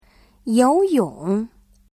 語彙詳細 HOME > 文字検索： y > 游泳 yóuyǒng 文 文法モジュールへリンク 会 会話モジュールへリンク 発 発音モジュールへリンク 游泳 yóuyǒng ※ご利用のブラウザでは再生することができません。 (1)泳ぐ <例文> 他会 游泳 。